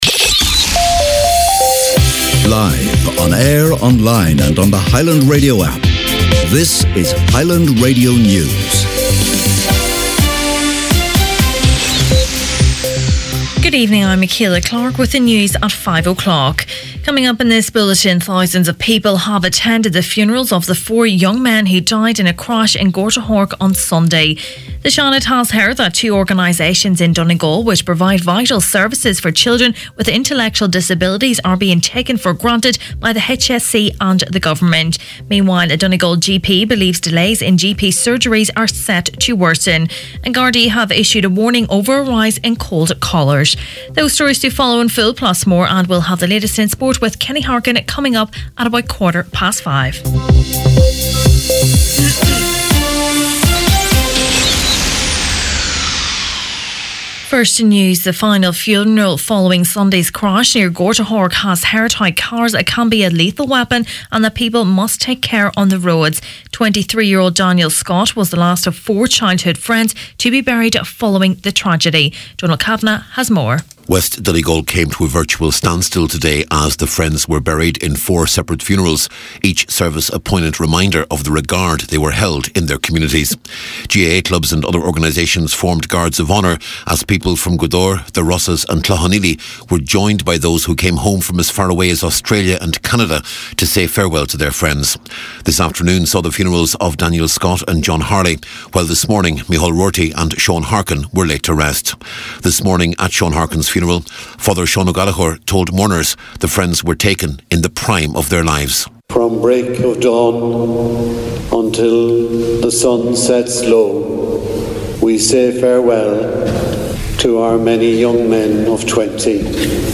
Main Evening News, Sport and Obituaries Thursday January 31st